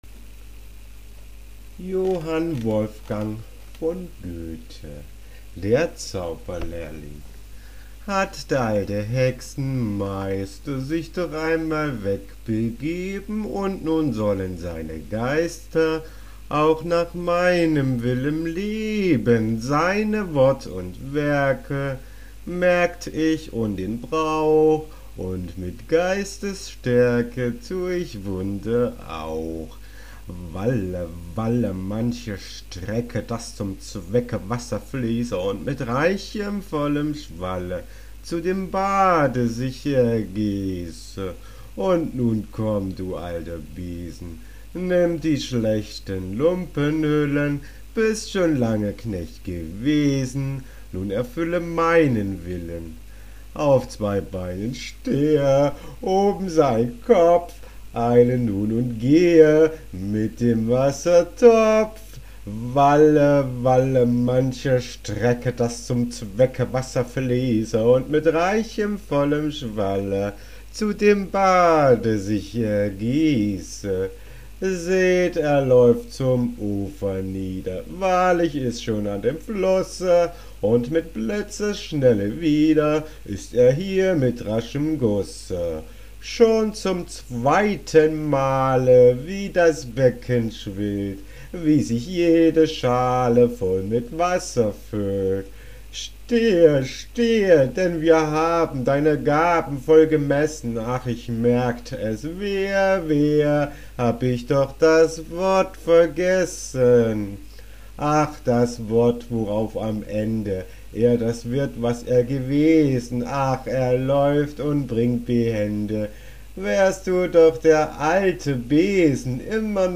Gedichtinterpretationen als Audiofiles